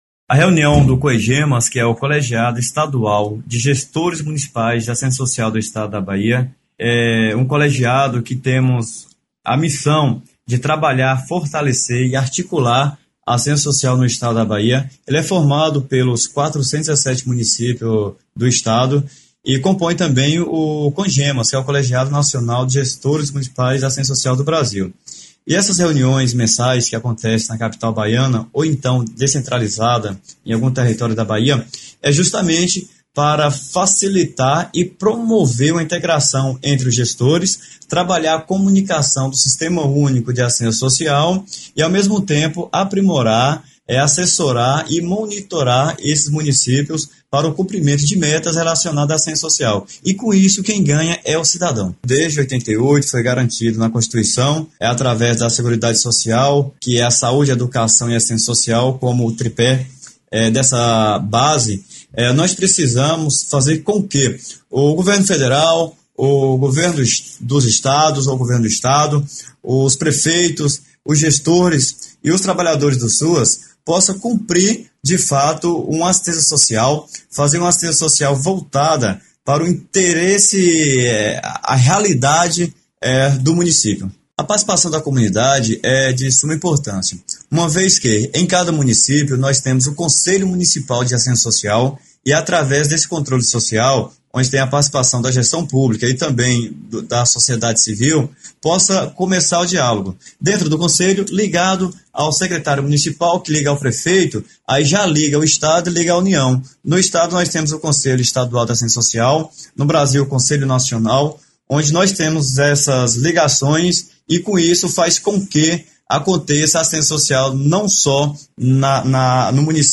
Ouça  a declaração do gestor para o Portal Saúde no ar.